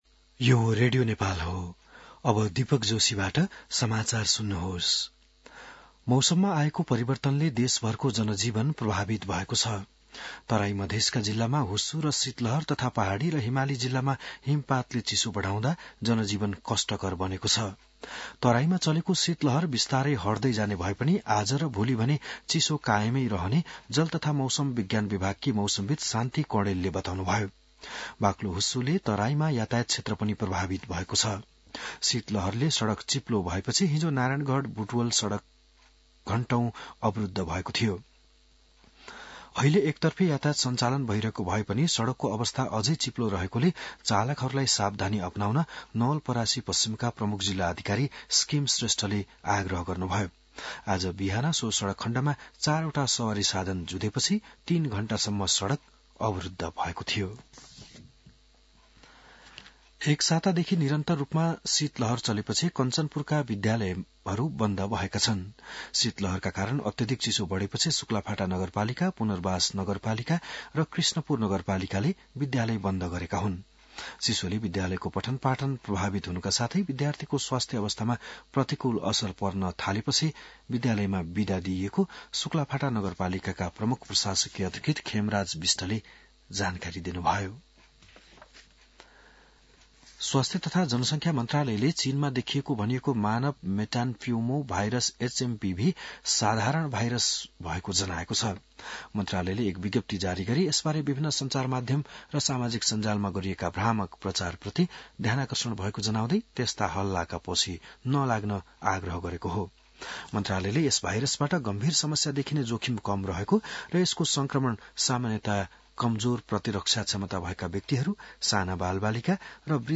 बिहान १० बजेको नेपाली समाचार : २६ पुष , २०८१